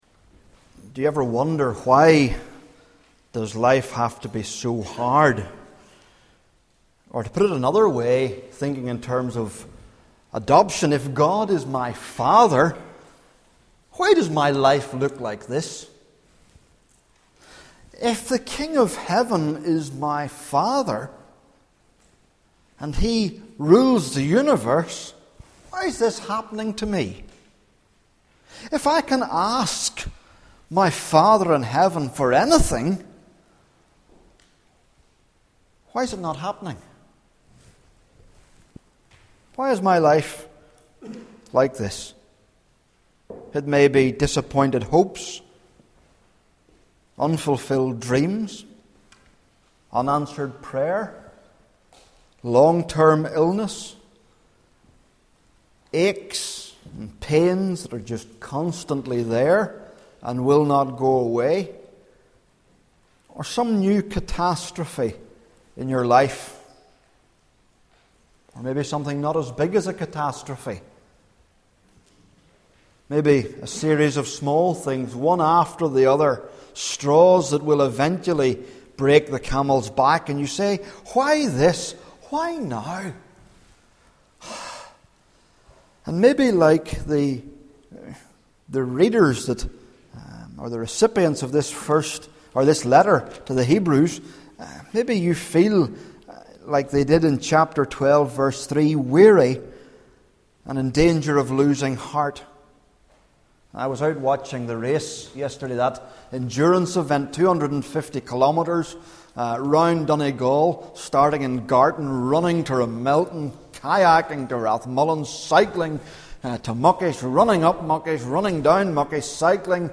Hear the latest sermons preached in NLF, or browse the back catalogue to find something to feed your soul.